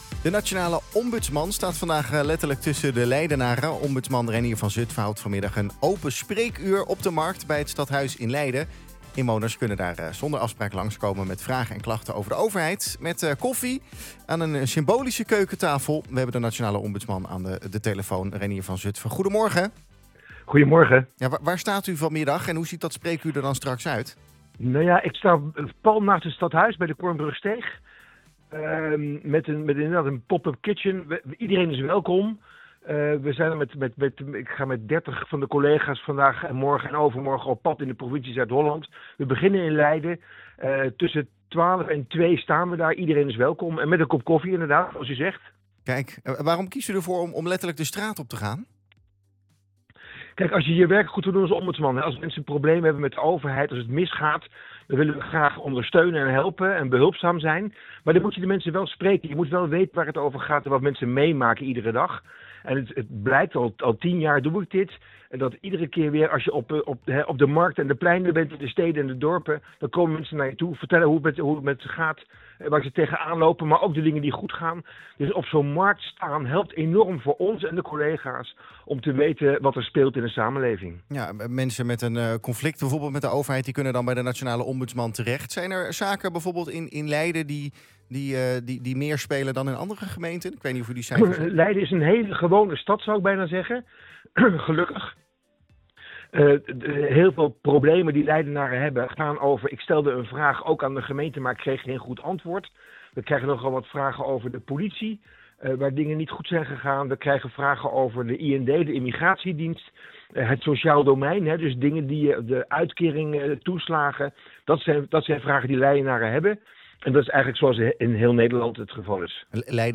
Reinier van Zutphen vertelt in de ochtendshow van Centraal+ over zijn komst naar Leiden:
Interview Leiden Maatschappij klachten Markt Ombudsman Samenleving spreekuur